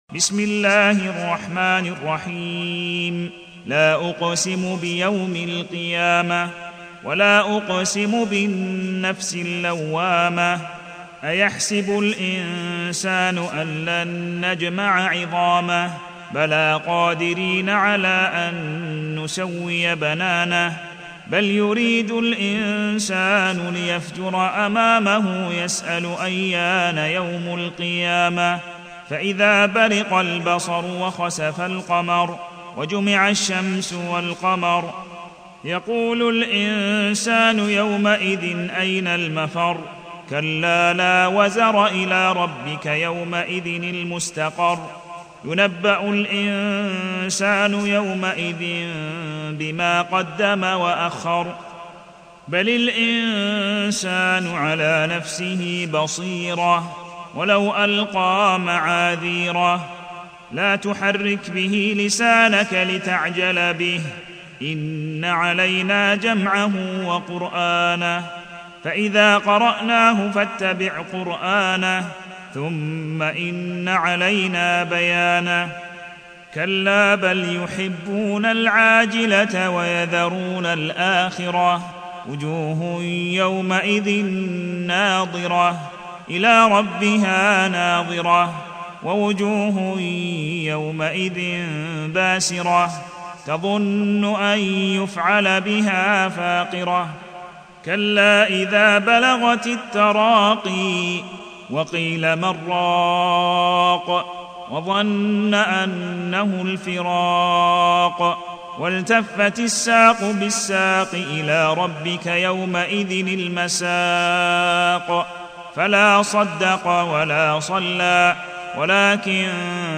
ياسر بن إبراهيم المزروعي المصحف المرتل - رويس وروح عن يعقوب - القيامة